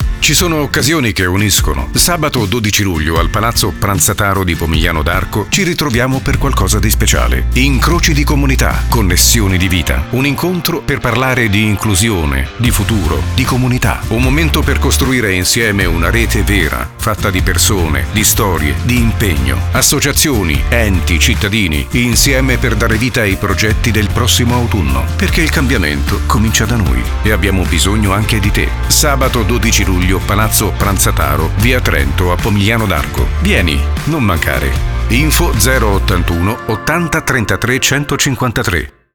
E se volete già immergervi nell’atmosfera dell’evento, non perdete l’intervento radiofonico andato in onda su Radio Punto Zero: ascoltatelo